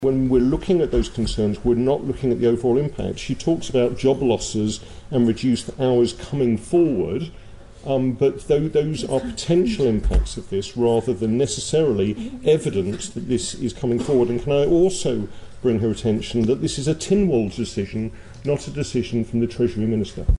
In response, he told the House of Keys no assessments of businesses or costs have been carried out since October last year, and only between 2 and 5 percent receive the full minimum wage. But he admits he understands the concerns of the business community: